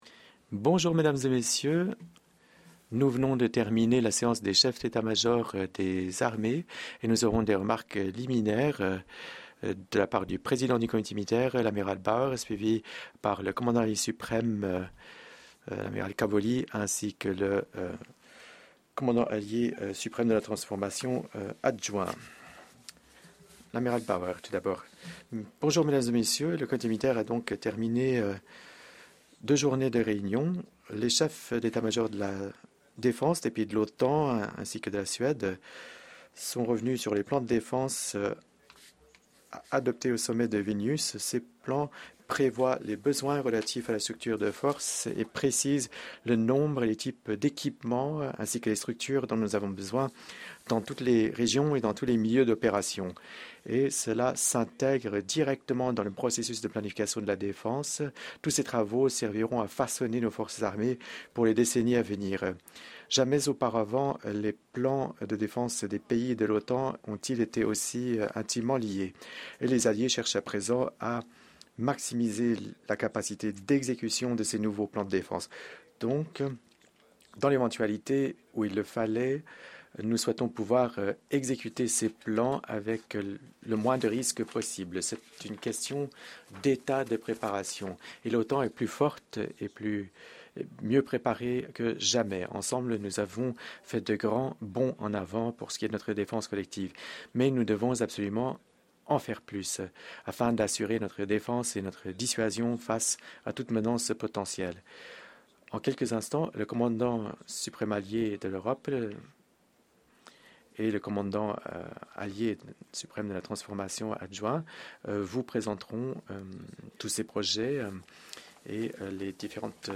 ENGLISH - Joint Press Conference by the Chair of the NATO Military Committee, Admiral Rob Bauer with Supreme Allied Commander Europe, General Christopher Cavoli and Deputy Supreme Allied Commander Tra